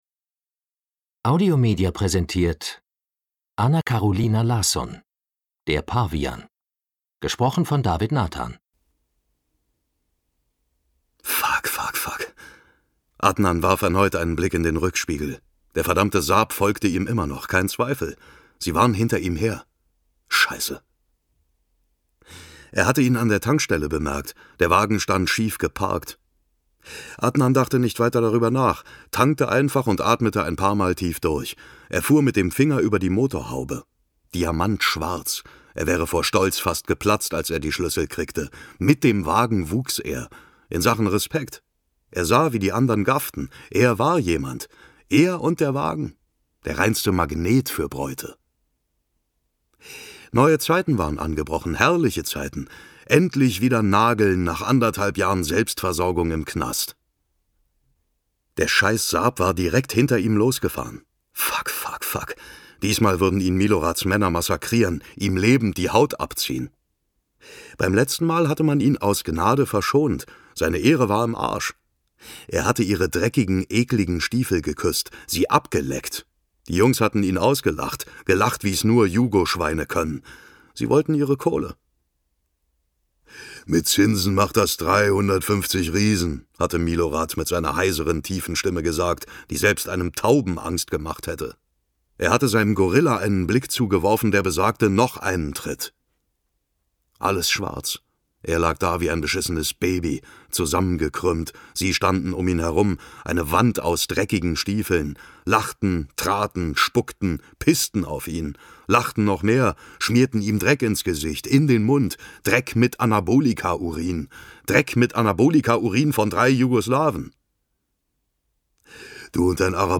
Mit seiner herausragenden Erzählkunst fesselt er den Hörer und zieht ihn mit in die Geschichte hinein.
Mitarbeit Sprecher: David Nathan